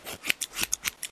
haircut5.ogg